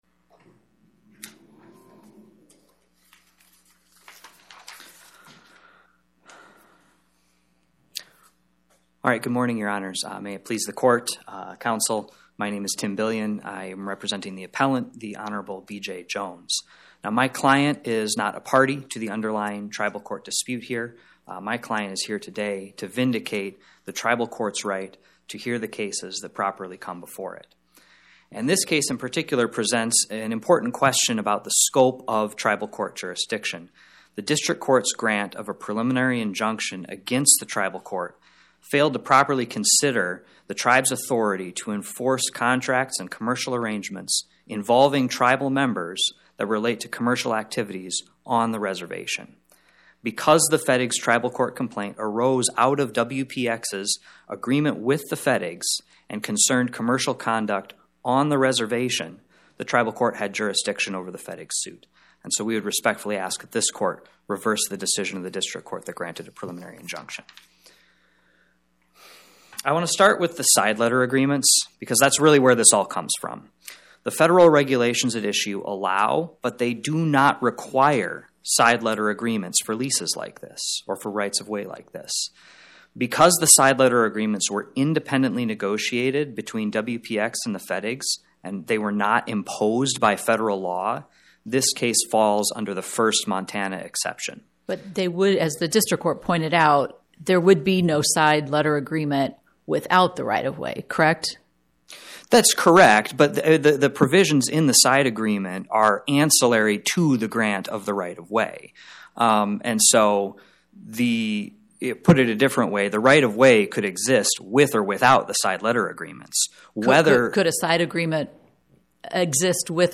My Sentiment & Notes 25-1083: WPX Energy Williston, LLC vs Honorable B.J. Jones Podcast: Oral Arguments from the Eighth Circuit U.S. Court of Appeals Published On: Thu Oct 23 2025 Description: Oral argument argued before the Eighth Circuit U.S. Court of Appeals on or about 10/23/2025